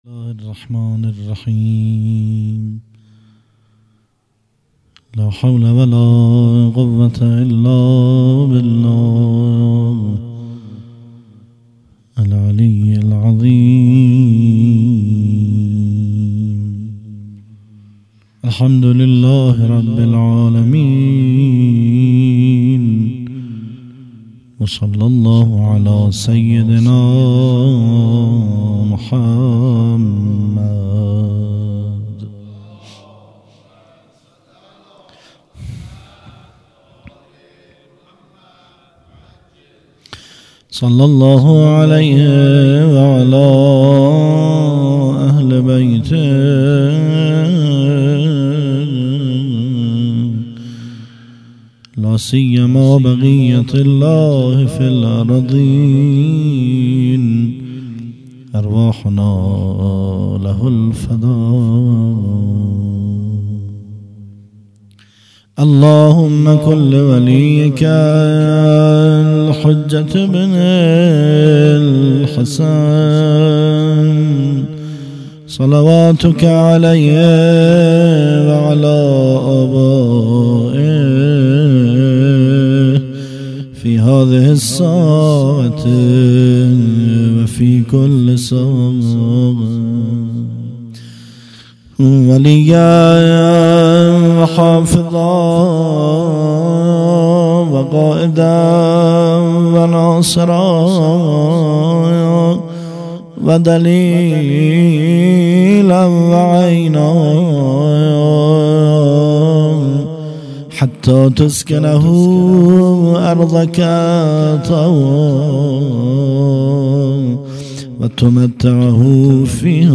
هیئت مکتب الزهرا(س)دارالعباده یزد - سخنران